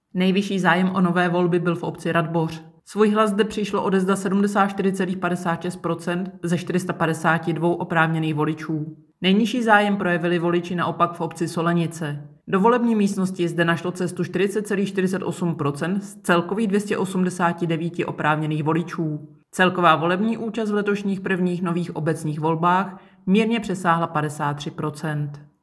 Vyjádření Evy Krumpové, 1. místopředsedkyně Českého statistického úřadu, soubor ve formátu MP3, 1004.06 kB